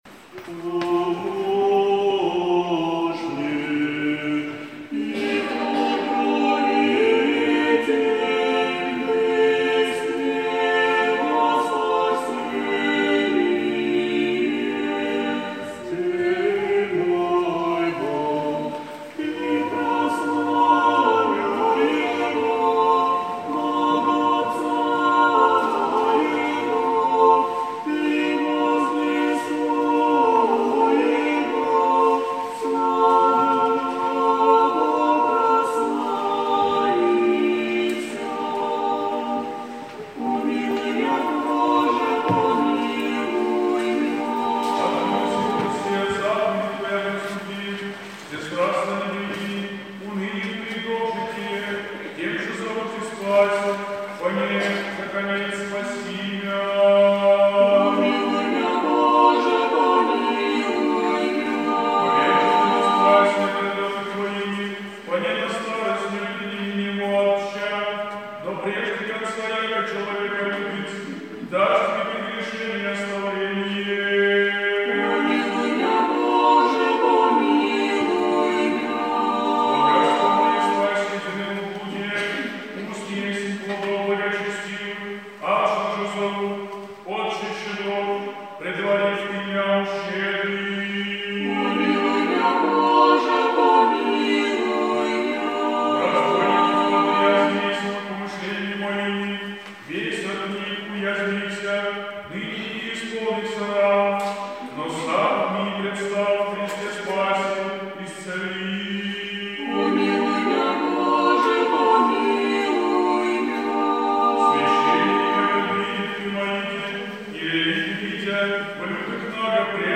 На богослужении молились прихожане собора и других храмов Павловского благочиния.